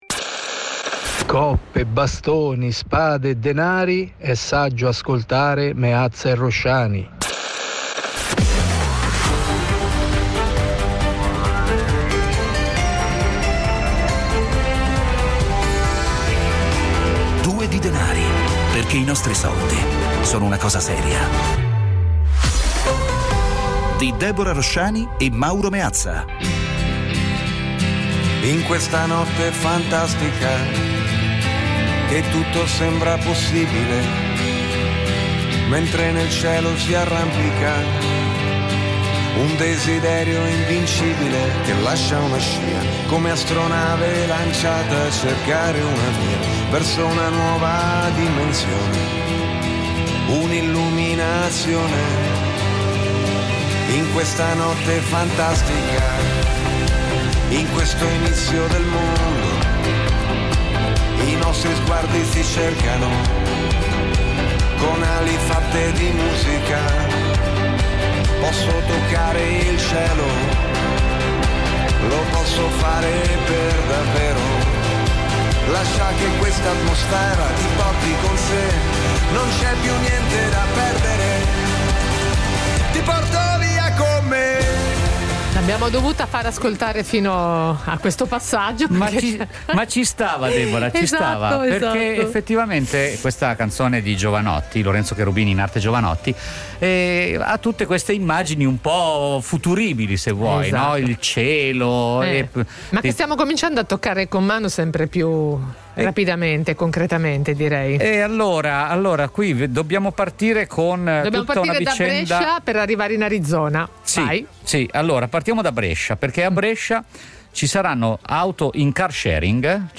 Sono davvero tanti gli aspetti della finanza personale che è bene approfondire, perché toccano da vicino gli aspetti più importanti delle nostre vite. Ogni giorno, su Radio 24, in questo spazio vengono affrontati con l'aiuto degli ospiti più competenti, uno sguardo costante all'attualità e i microfoni aperti agli ascoltatori.
La cifra, da sempre, è quella dell’ "autorevoleggerezza" : un linguaggio chiaro e diretto, alla portata di tutti.